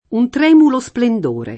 tremulo [tr$mulo] (antiq. tremolo [tr$molo]) agg. — es.: un tremulo splendore [